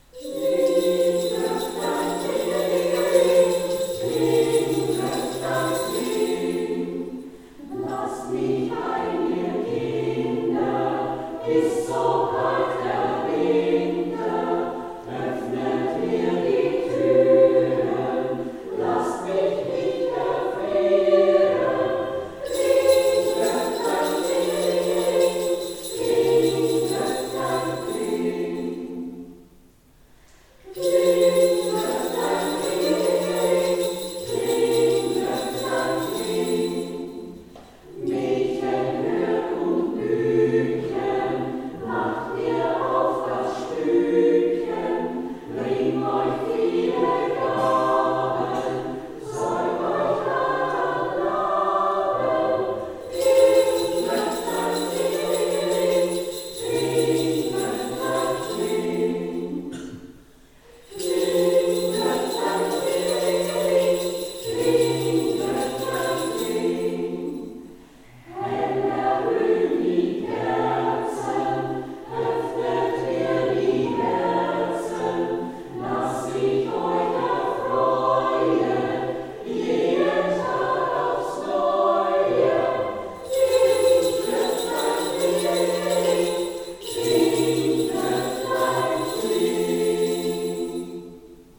Hier ein Mitschnitt vom Adventkonzert 2022 in der Pfarrkirche Thal vom 28.11.2022